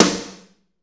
Index of /90_sSampleCDs/Northstar - Drumscapes Roland/DRM_Slow Shuffle/KIT_S_S Kit 2 x